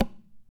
CHARANGNOIAV.wav